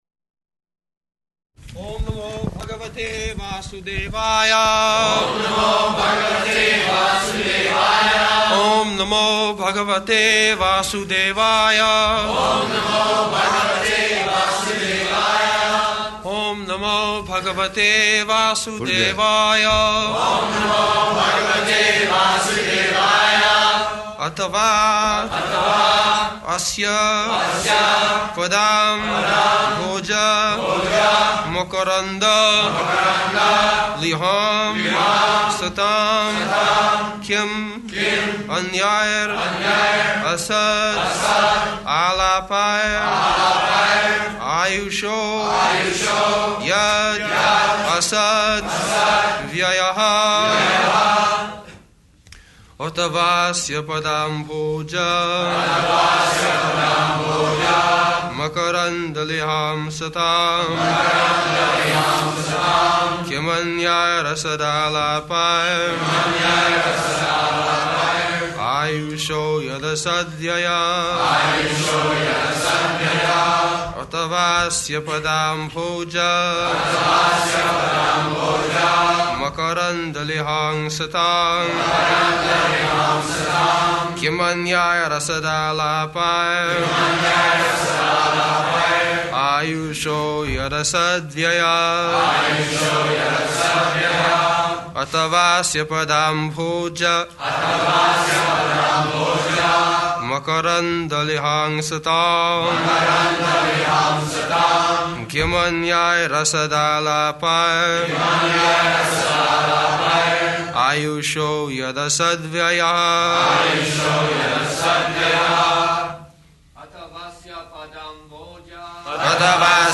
-- Type: Srimad-Bhagavatam Dated: January 3rd 1974 Location: Los Angeles Audio file
[devotees repeat] [leads chanting of verse, etc.] athavāsya padāmbhoja- makaranda-lihāṁ satām kim anyair asad-ālāpair āyuṣo yad asad-vyayaḥ [ SB 1.16.6 ] Prabhupāda: Who is that?